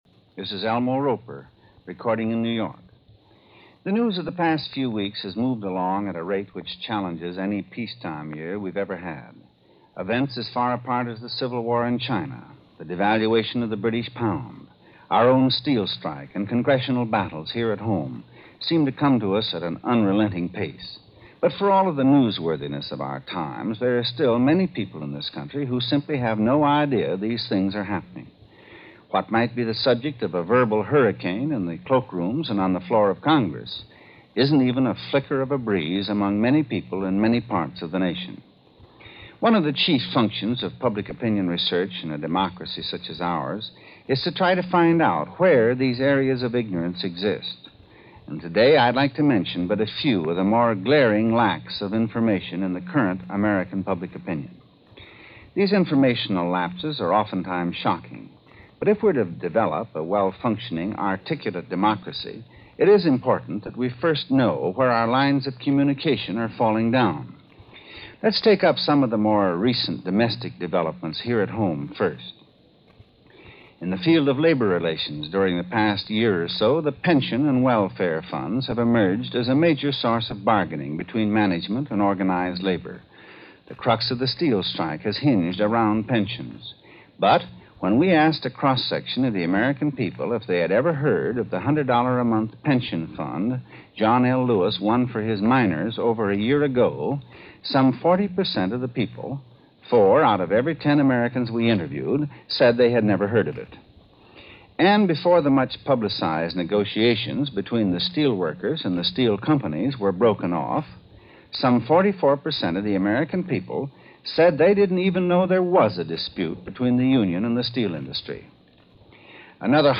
America's Little History Problem: Spectacularly Uninformed - November 6, 1949 - Elmo Roper hosts Where People Stand - November 6, 1949
Case in point – this broadcast, part of the CBS Radio series Where the People Stand, broadcast on November 6, 1949, offered up more than a few perplexing facts and figures over just how completely uninformed we were in 1949.